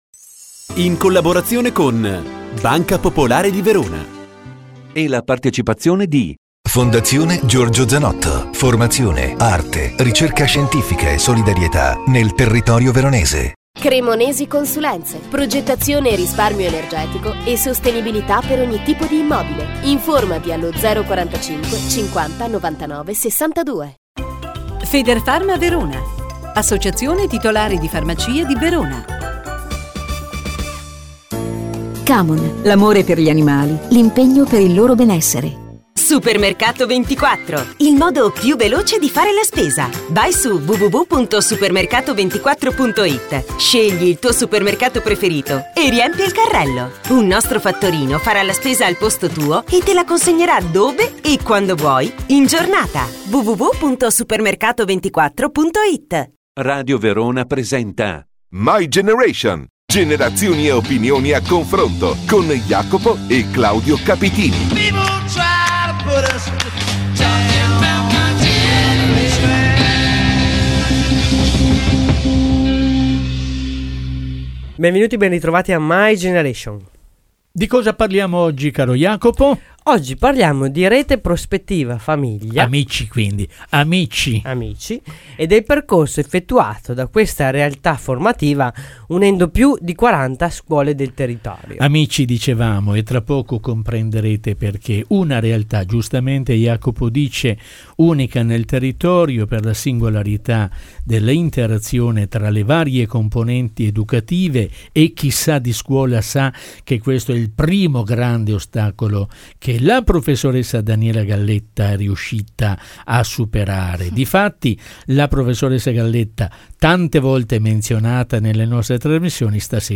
Disponibile la registrazione audio dell’intervista del giorno 4 maggio 2015 a Radio Verona